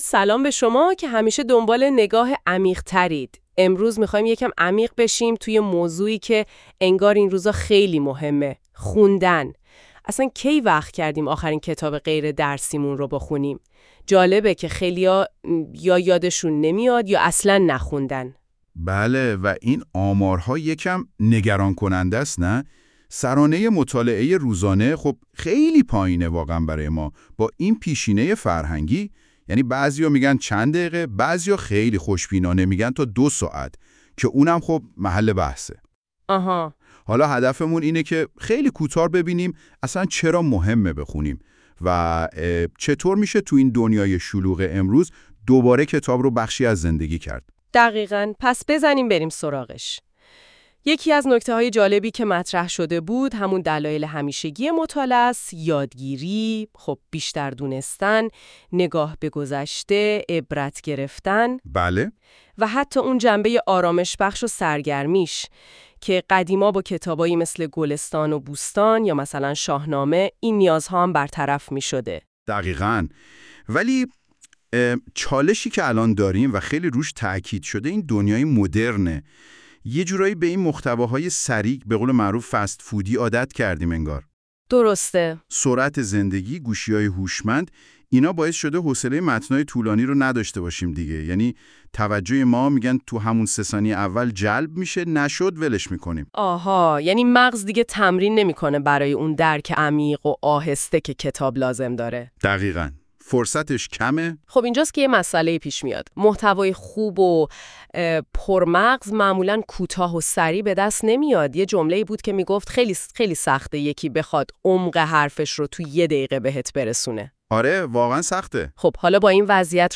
خلاصه این کلاس در قالب پادکست
[با توجه به اینکه پادکست توسط هوش مصنوعی تولید می شود، ممکن است برخی از کلمات اشتباه تلفظ شود؛ همچنین ممکن است برخی مباحث حلقه در پادکست ذکر نشده و یا مطالبی خارج از حلقه در آن آورده شده باشد]